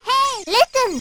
match-start.wav